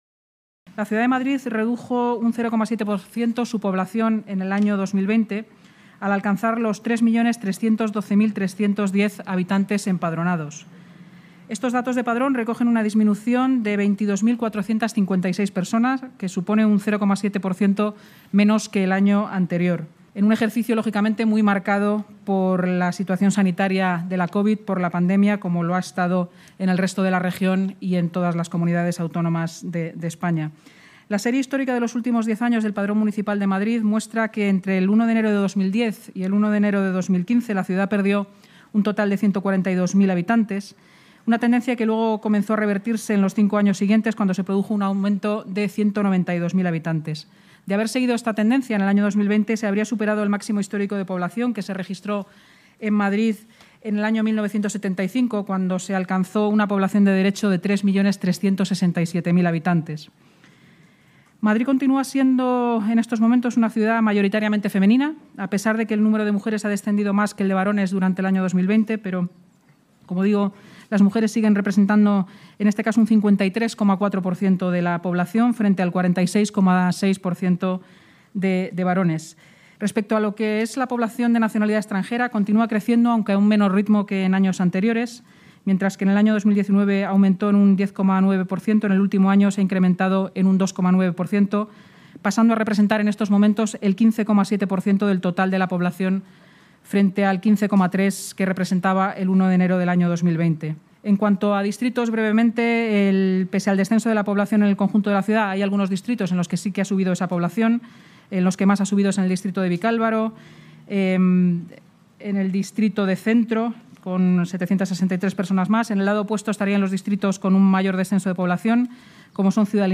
Nueva ventana:La portavoz municipal Inmaculada Sanz explica, en la rueda de prensa posterior a la Junta de Gobierno, los detalles sobre la aprobación de la revisión del Padrón Municipal